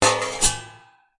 描述：用刀刮一块金属片。由RØDEM3修改。
Tag: 金属